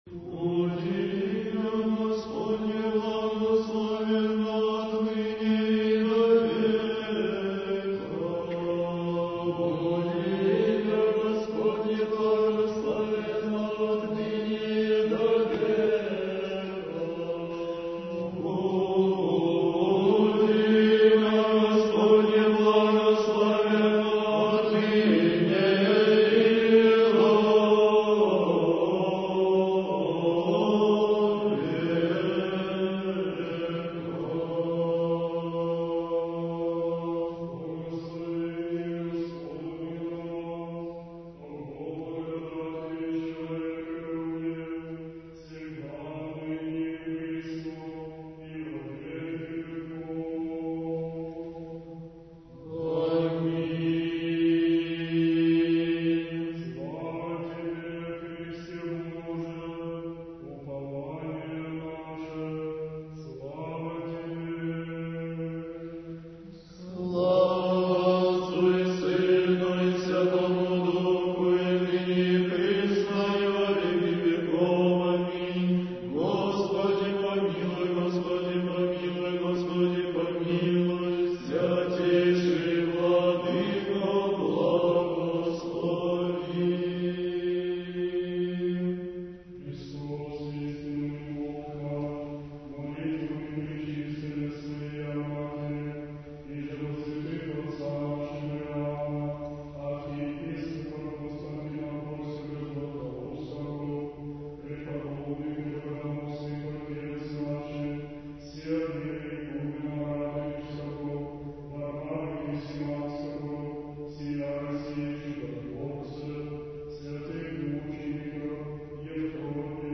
Архив mp3 / Духовная музыка / Русская / Хор Троице-Сергиевой Лавры под управлением архимандрита Матфея (Мормыля) / Литургия в Черниговско-Гефсиманском скиту /